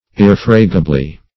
Ir*ref"ra*ga*bly, adv.